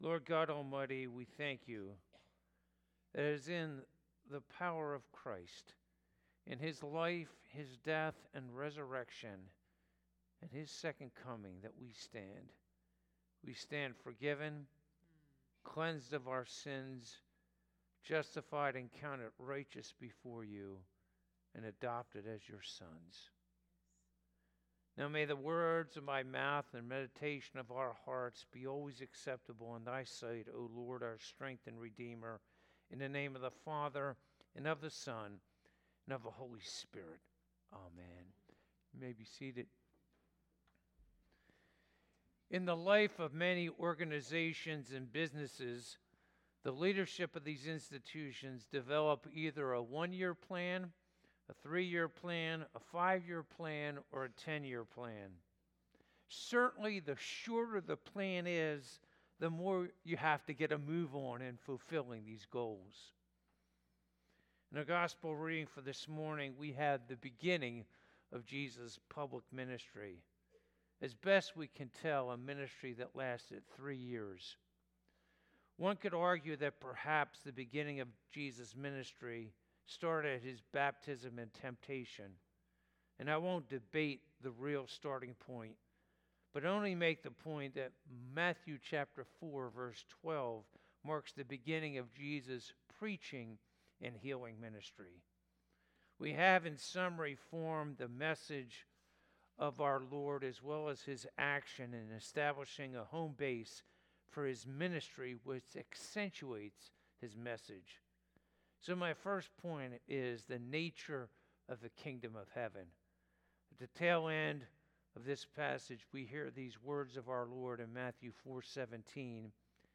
Passage: Matthew 4:12-17 Service Type: Sunday Morning